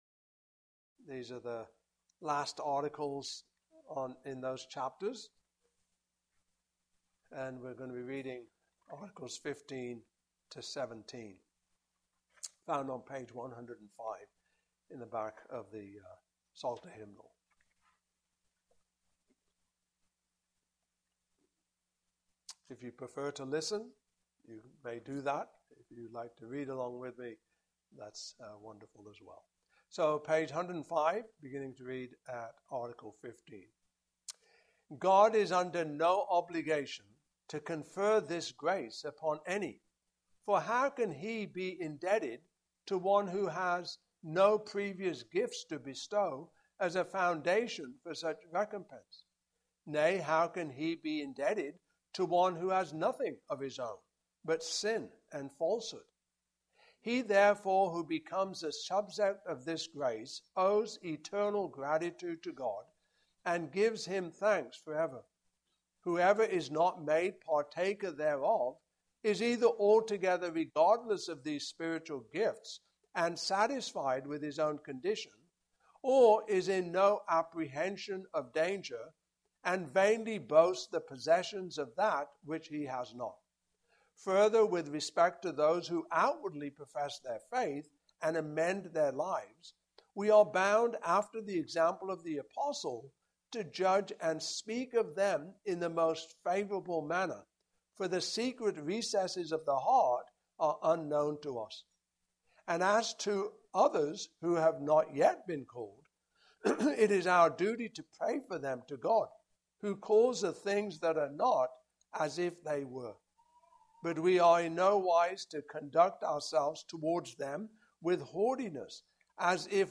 Series: Canons of Dordt Passage: I Peter 2:1-12 Service Type: Evening Service